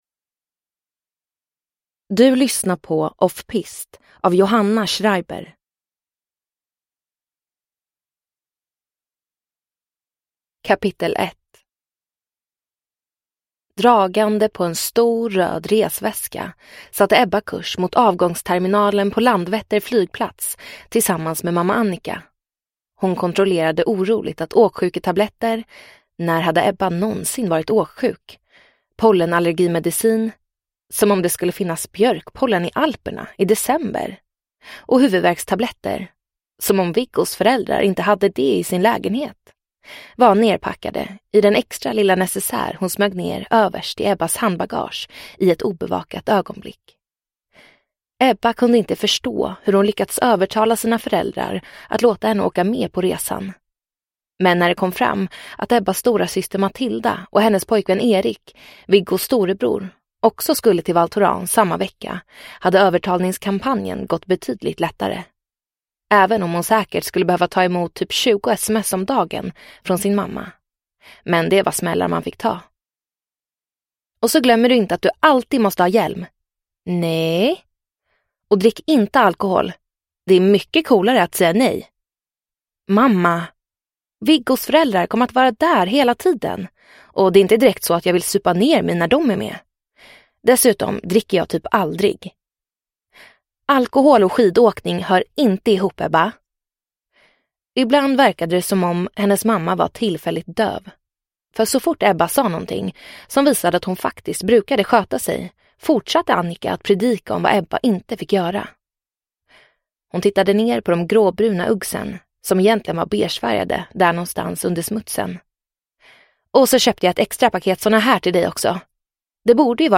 Offpist – Ljudbok – Laddas ner